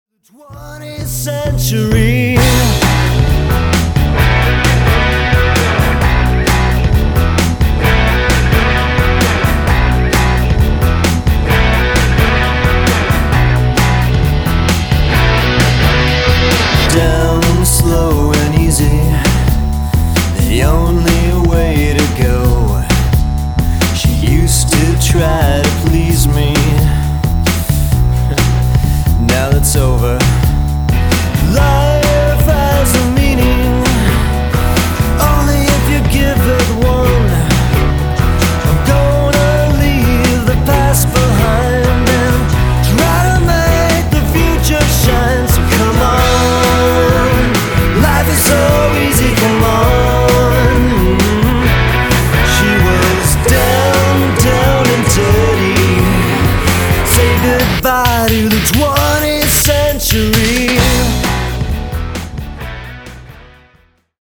Sexy, Groovy Pop